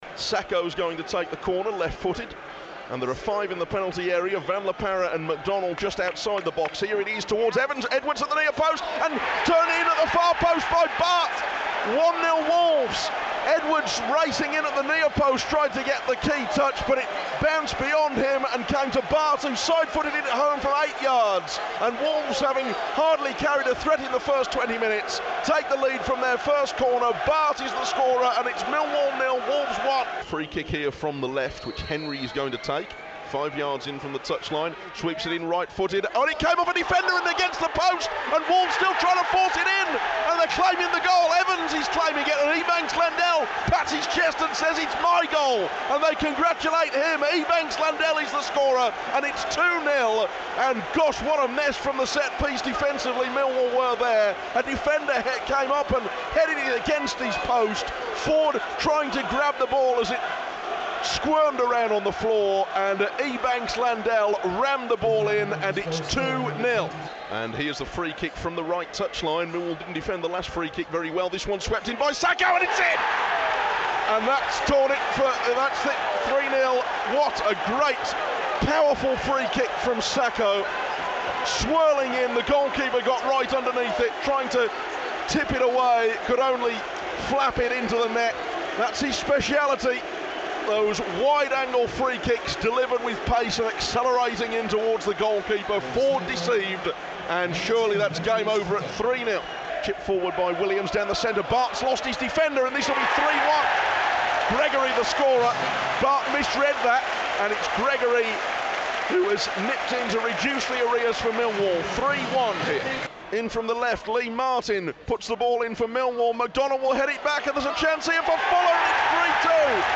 describes the action and speaks to Kenny Jackett at the New Den.